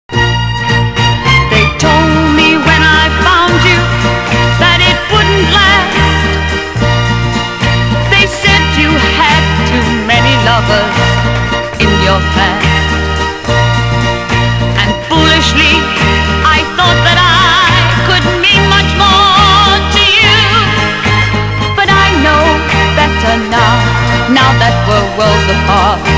(税込￥1650)   POP